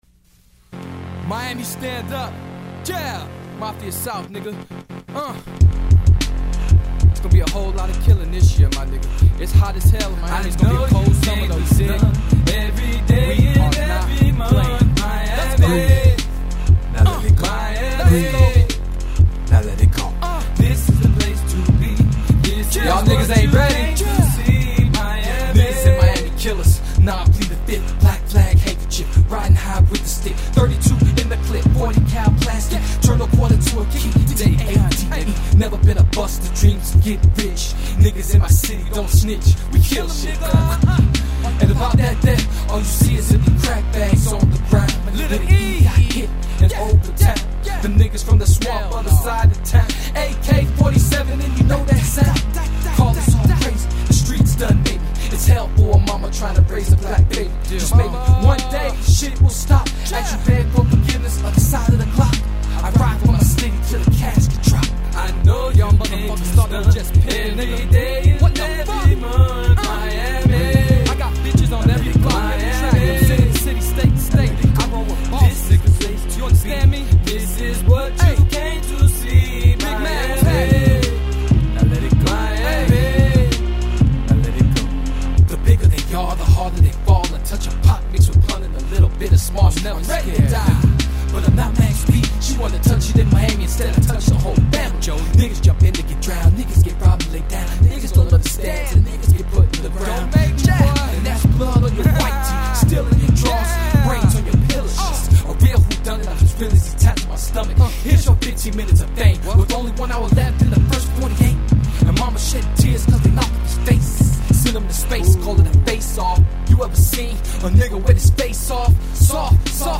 rap Gangsta Rap
hip hop